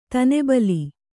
♪ tane bali